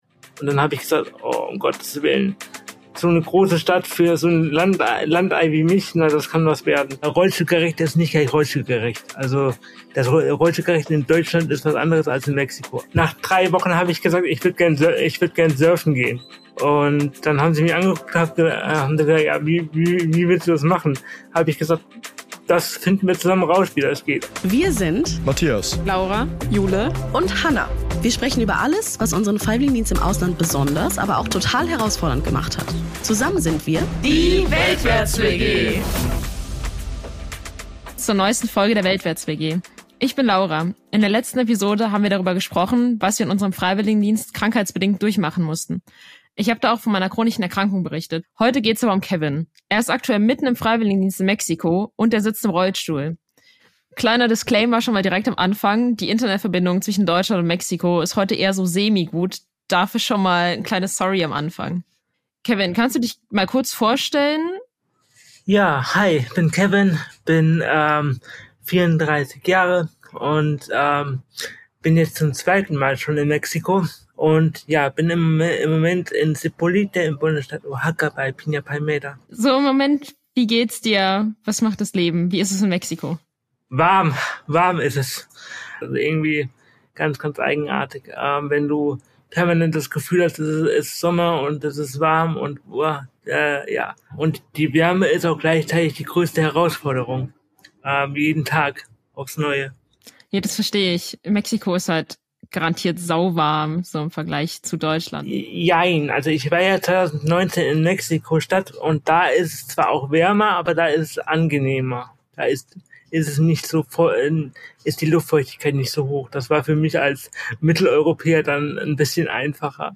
Insider-Talk